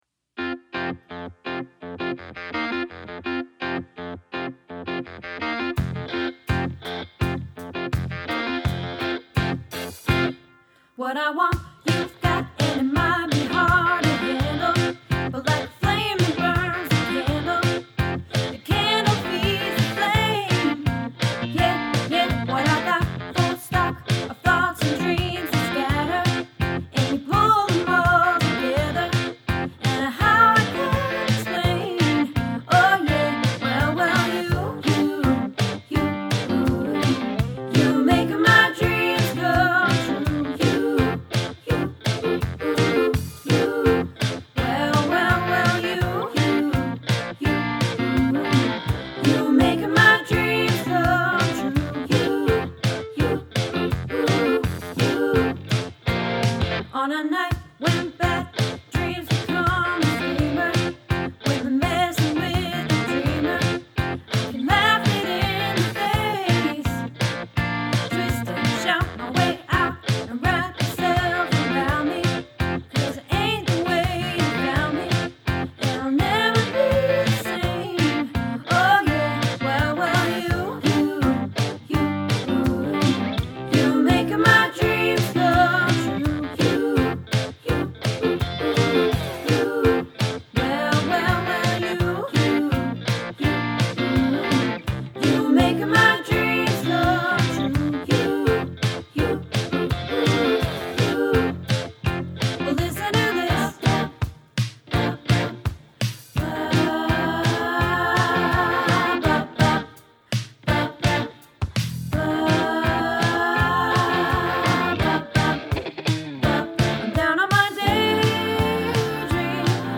You Make My Dream - Practice